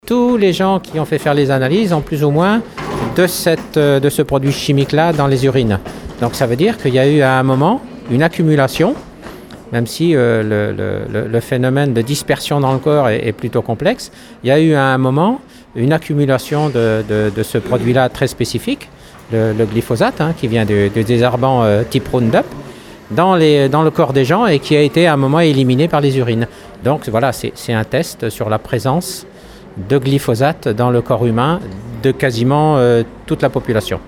Ce matin, devant le TGI de La Rochelle.